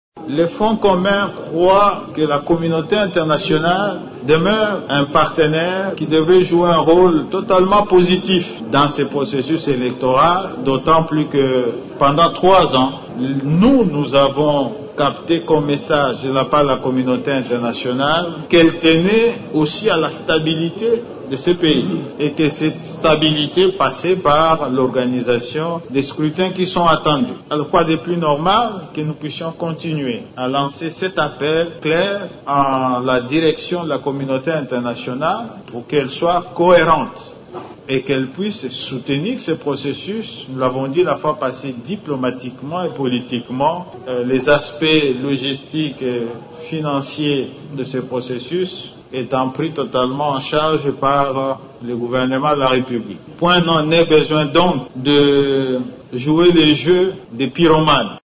Le Front commun pour le Congo (FCC) appelle la communauté internationale « à jouer un rôle positif » dans le processus électoral en République démocratique du Congo. Le coordonnateur de cette plateforme politique, Néhémie Mwilanya, l’a affirmé vendredi 2 novembre, lors d’un point de presse tenu à Kinshasa.